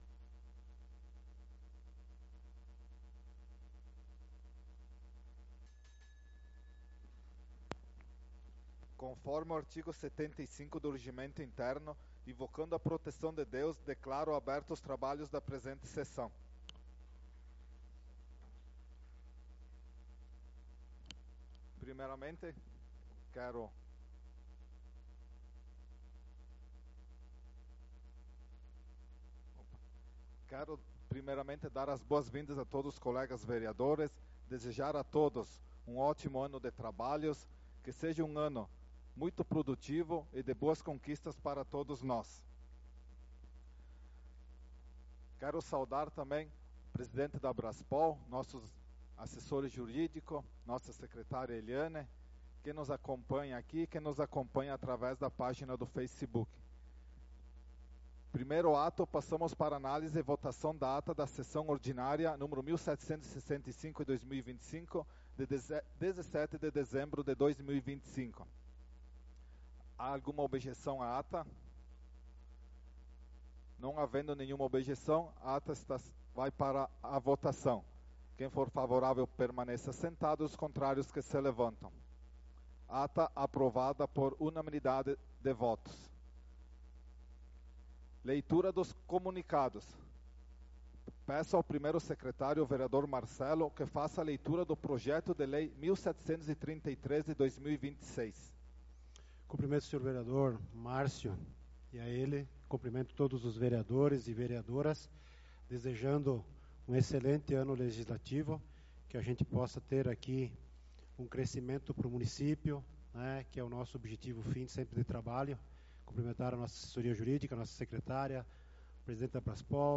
Sessão Ordinária do dia 04/02/2026